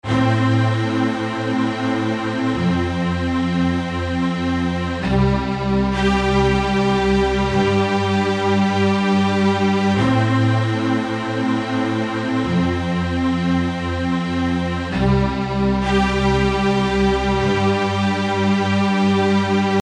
宝莱坞音乐类型97
标签： 97 bpm Orchestral Loops Synth Loops 3.33 MB wav Key : G Cubase
声道立体声